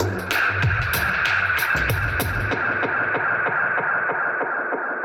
Index of /musicradar/dub-designer-samples/95bpm/Beats
DD_BeatFXB_95-03.wav